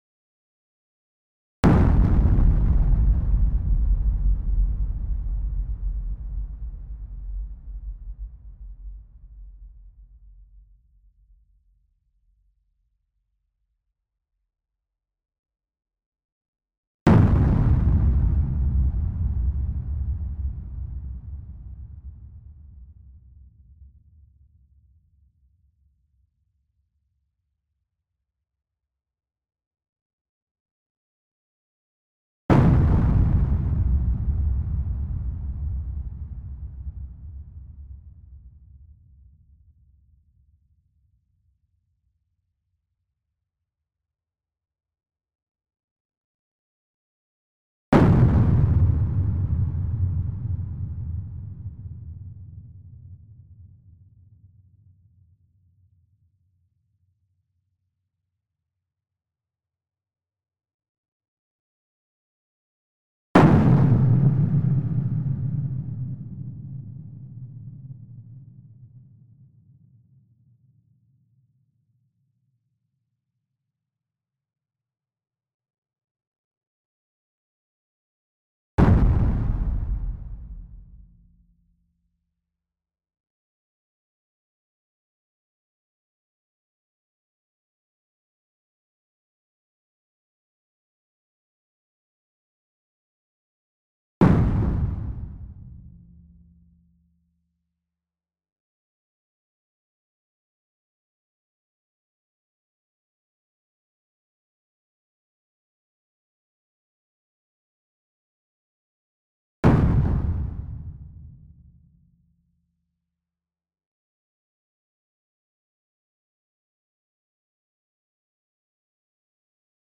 Heavy Weapons And Explosions Sound Effects – Clean-explosions – Free Music Download For Creators
Heavy_Weapons_And_Explosions_Sound_Effects_-_clean-explosions.mp3